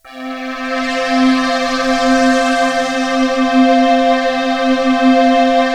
ALPS C4.wav